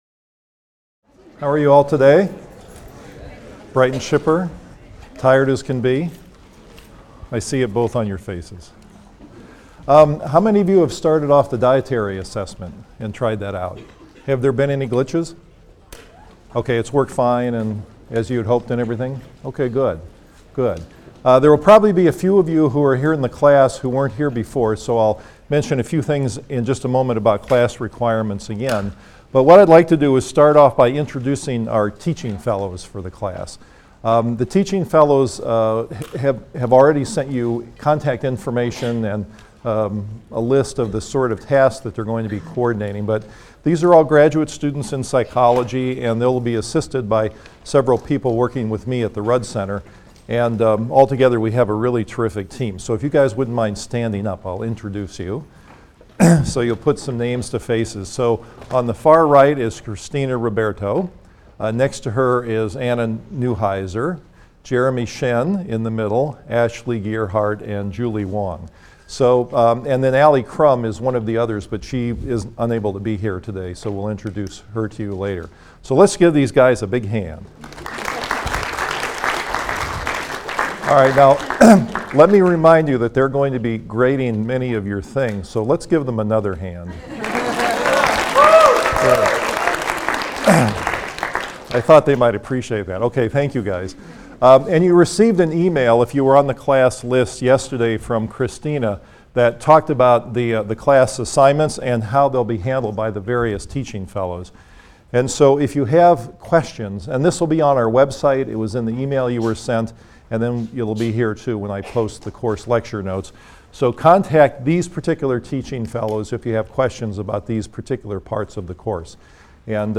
PSYC 123 - Lecture 3 - Biology, Nutrition and Health I: What We Eat | Open Yale Courses